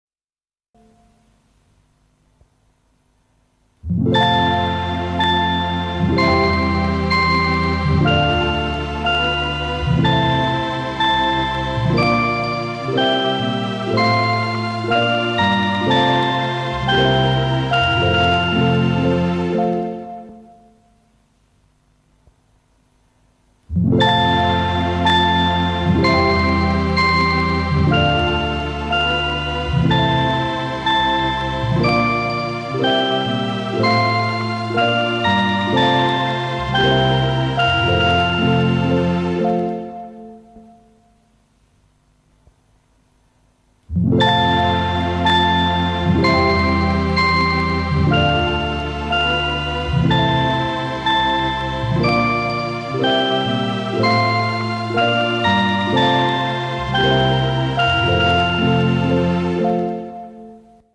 nel vecchio arrangiamento